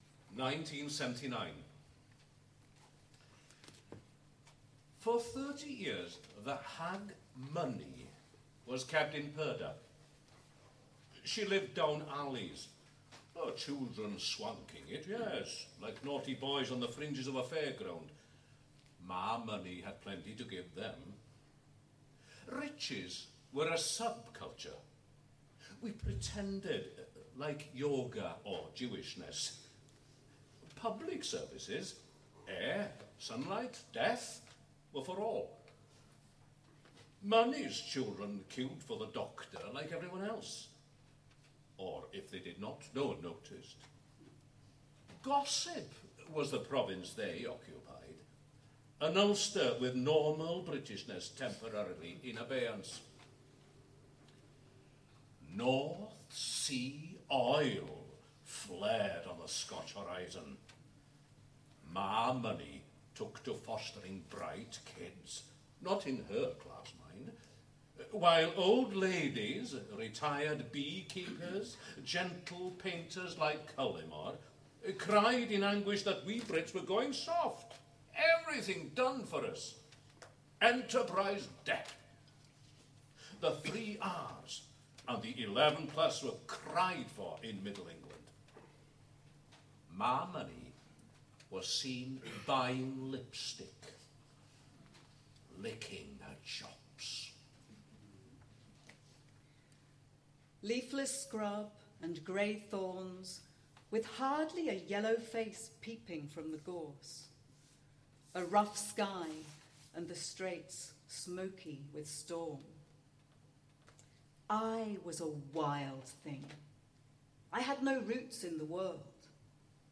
‘Tony Conran Remembered’ performed at Another Festival, Caernarfon July 2015